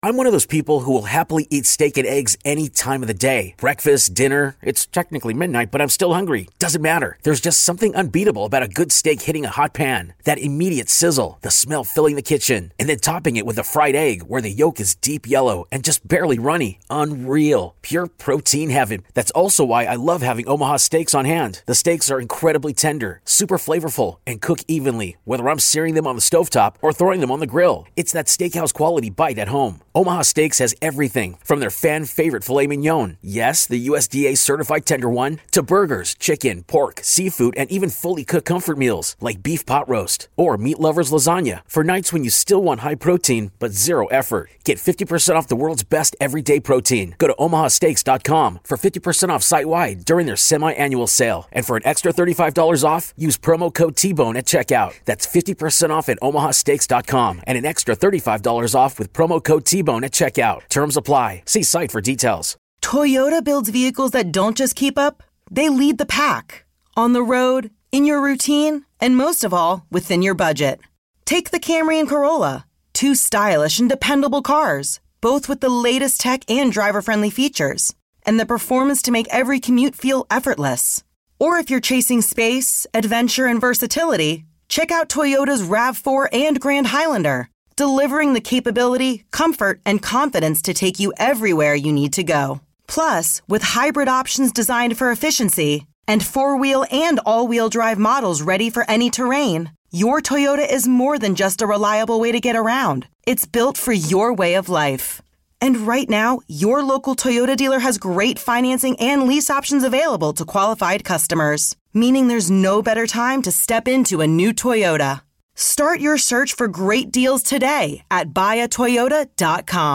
KCBS Radio's "Bay Current" is a bi-weekly news and information podcast keeping you current on Bay Area stories.